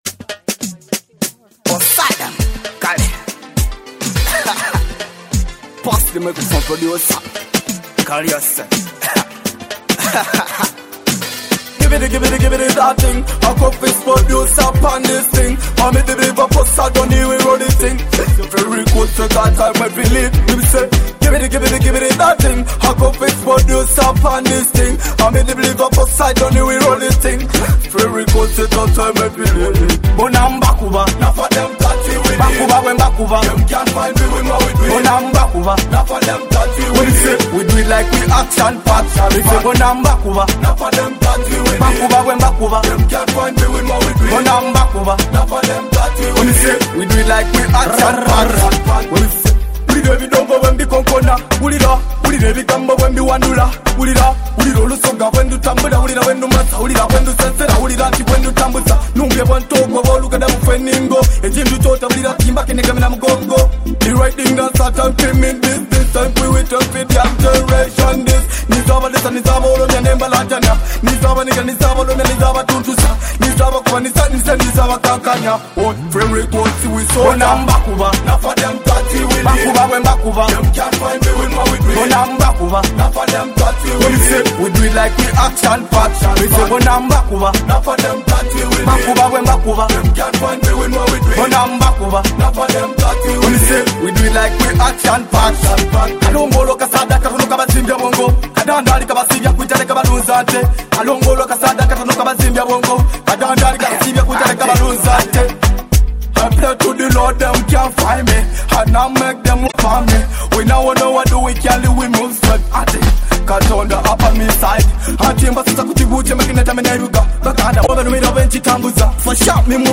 A high-energy dancehall hit perfect for parties and vibes.
empowering dancehall hit